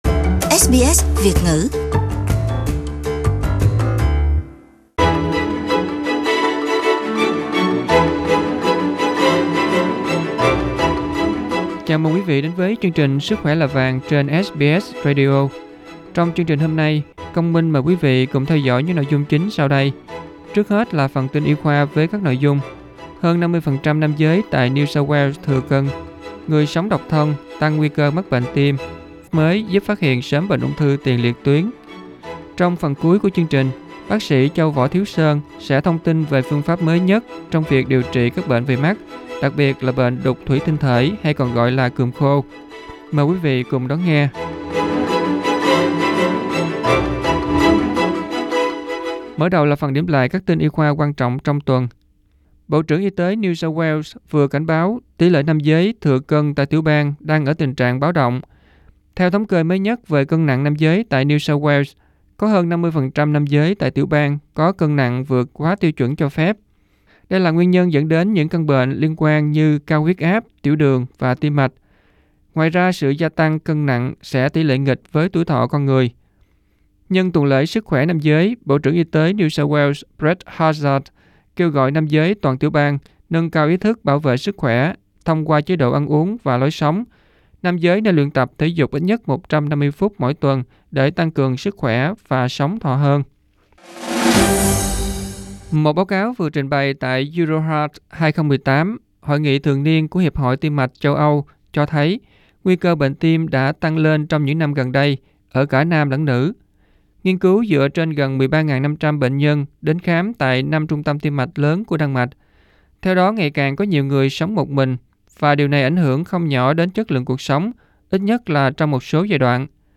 Mời vào phần audio để nghe nội dung phỏng vấn